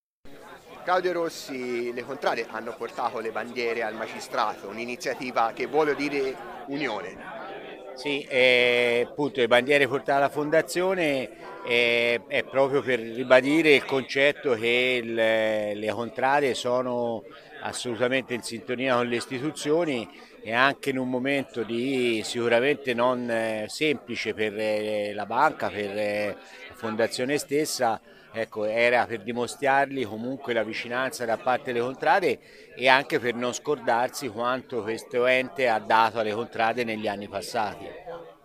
Qui sotto le dichiarazioni.